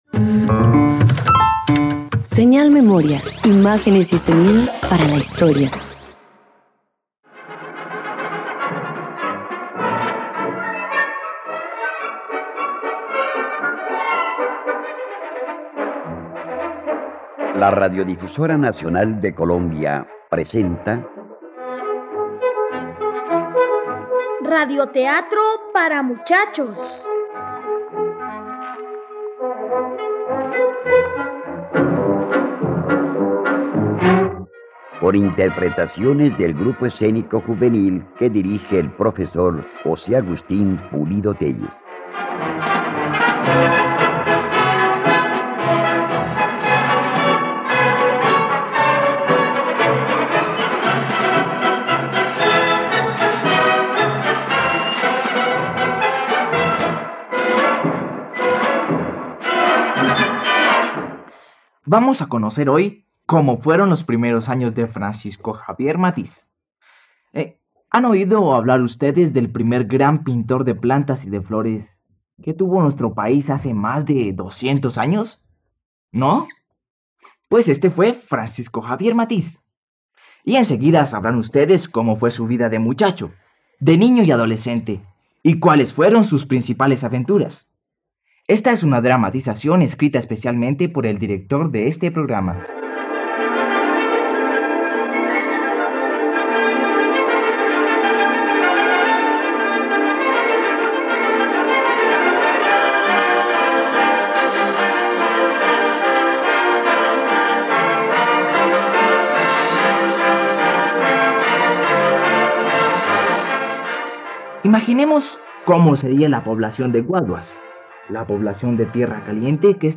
Francisco Javier Matiz - Radioteatro dominical | RTVCPlay